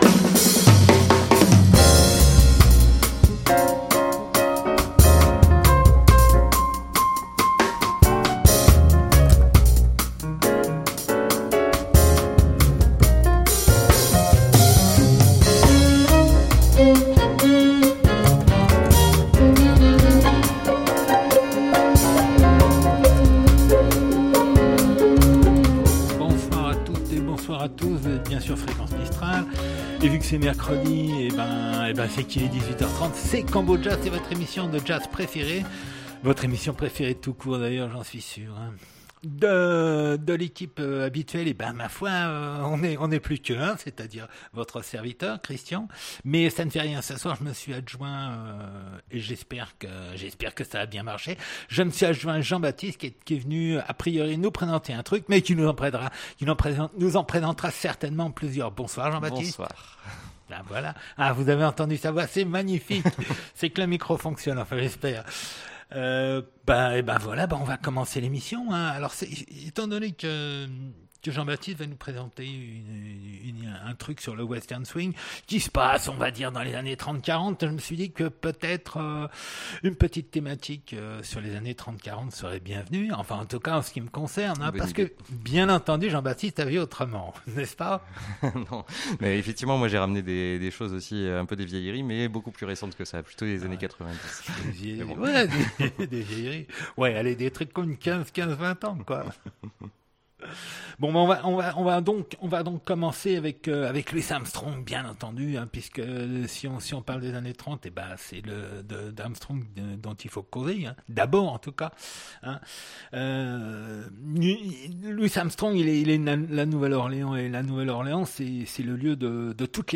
Combojazz du 19 Octobre 2016 Mercredi 19 Octobre 2016 Du jazz rien que du jazz 1 mercredi sur 2 de 18h30 à 20h00.